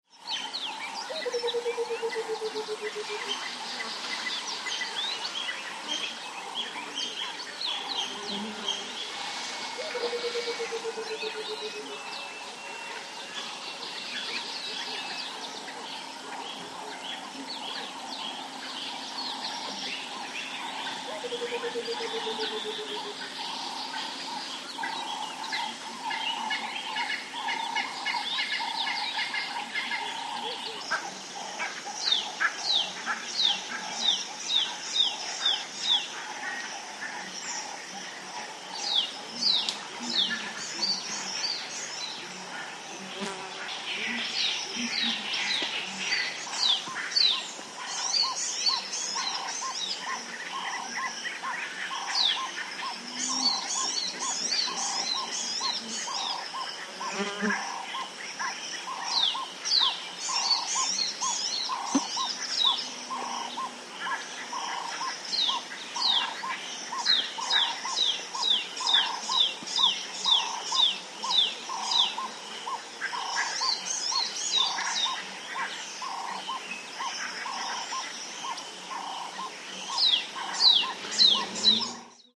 ANIMALS-BIRD BGS AFRICA: Dawn constant bird calls, light wind, distant bird calls, Moremi, Botswana. Kalahari atmosphere.